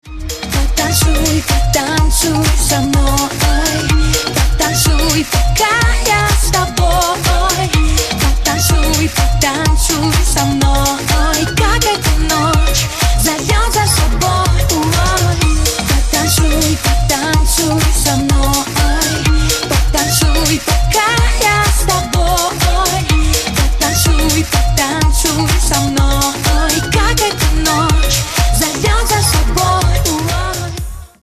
поп
dance